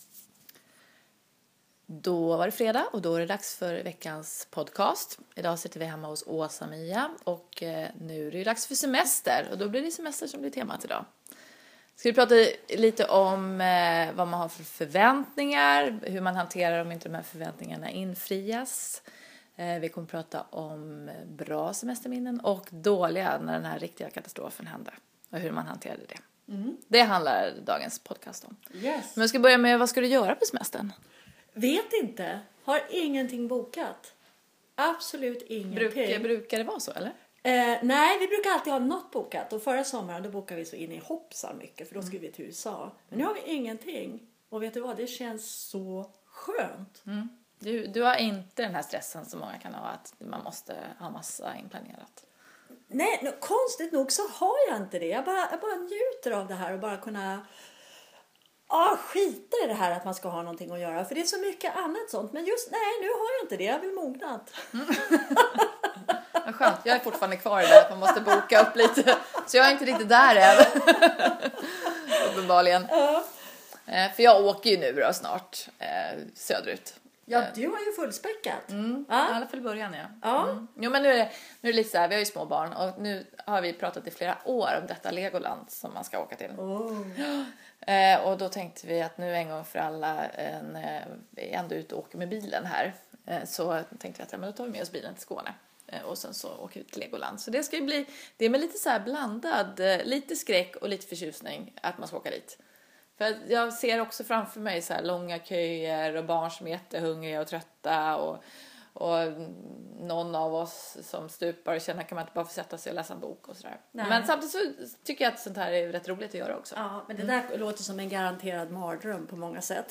Renews redaktörer pratar ledighet inför stundande semester.